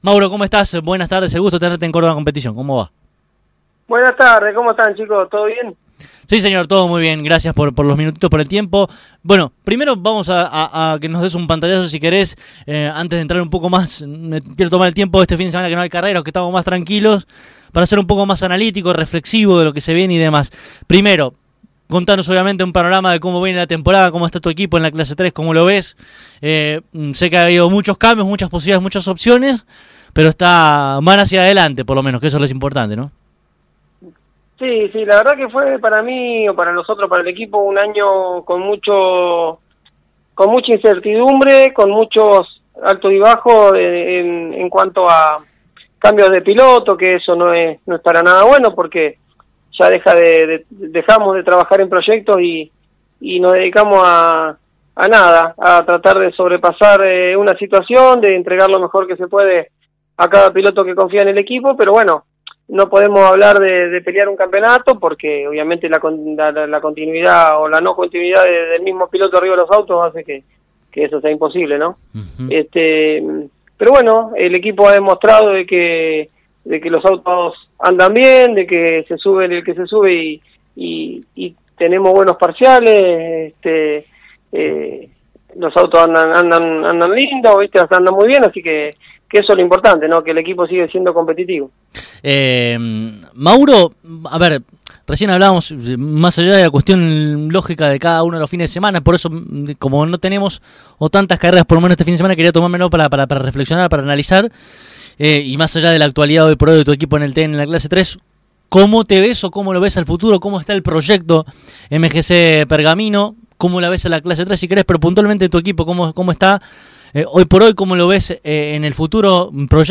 Aquí debajo podrás escuchar esta analítica entrevista de manera completa: